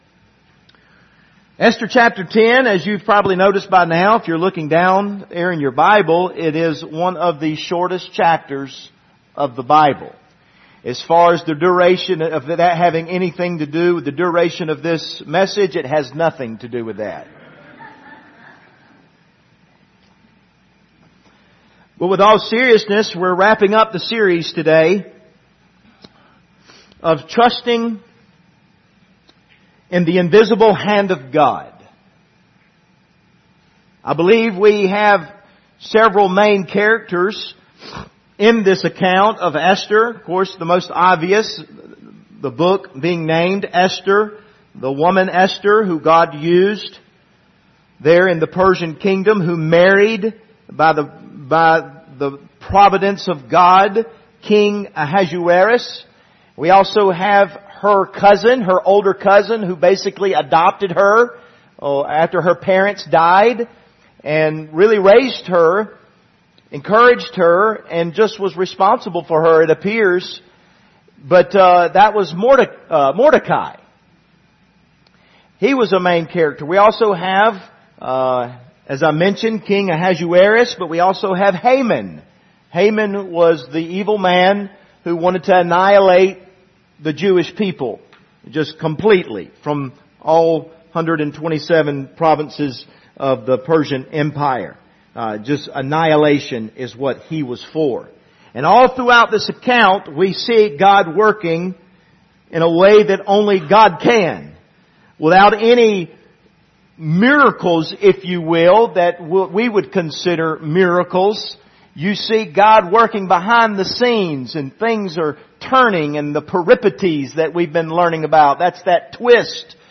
Esther Passage: Esther 10 Service Type: Sunday Morning Topics